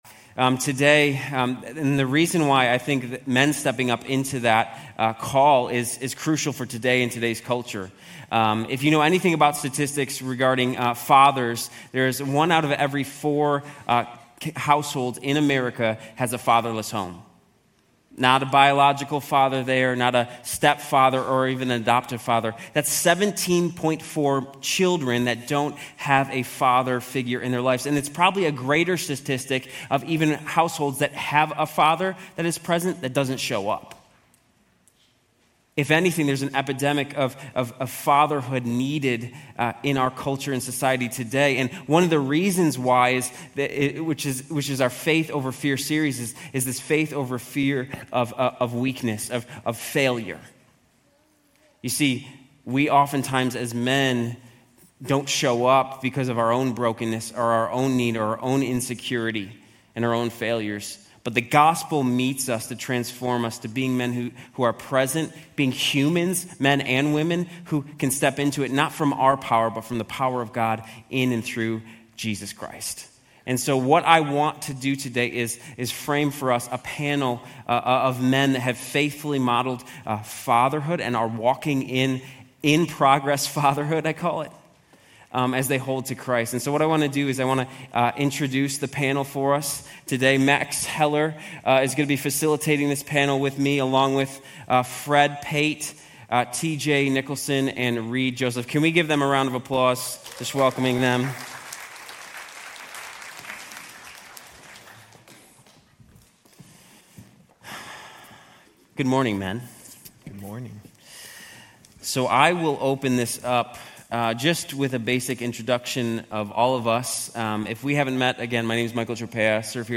Grace Community Church University Blvd Campus Sermons 6_15 University Blvd Campus Jun 16 2025 | 00:41:10 1x 00:00 / 00:41:10 Subscribe Share RSS Feed Share Link Embed